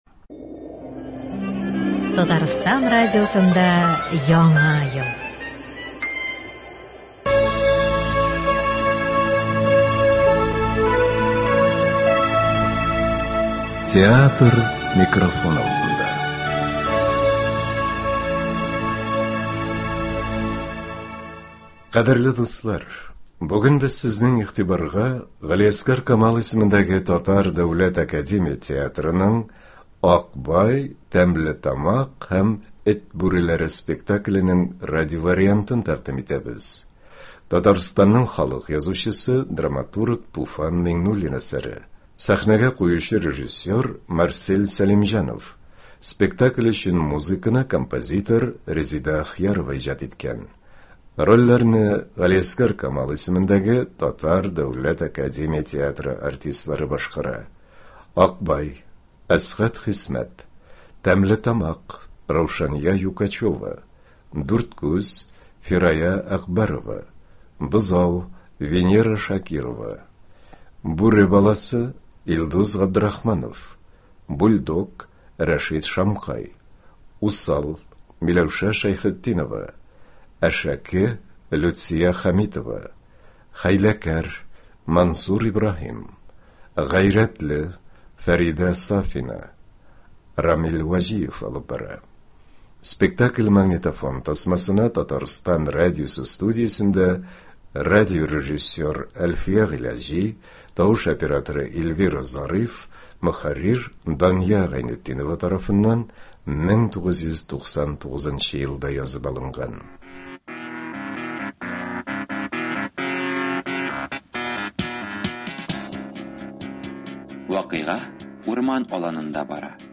Г.Камал ис. ТДАТ спектакле.
Радиоспектакль (08.01.24)